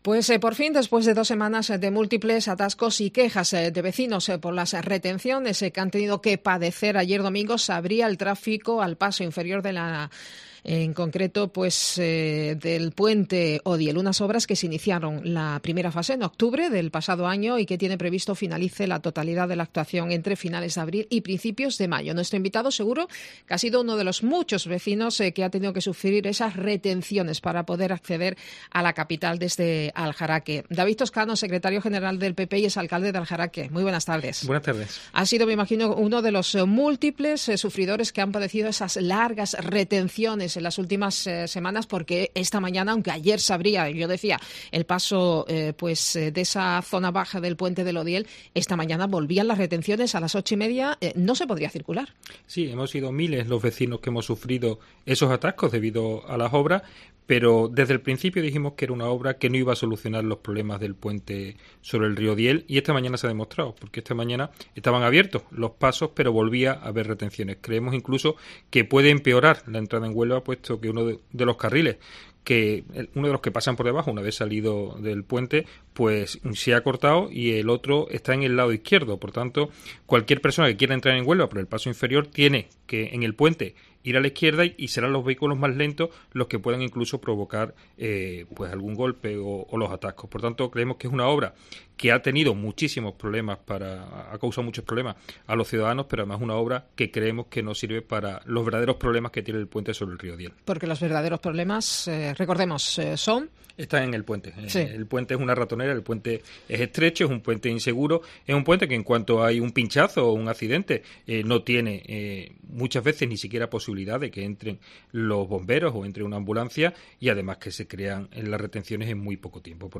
El Secretario General del PP y ex-alcalde de Aljaraque, David Toscano ha sido hoy nuestro invitado en el tiempo de Herrera en Cope. Él, ha sido uno de los miles de sufridores que han padecido las largas retenciones en el Puente del Odiel.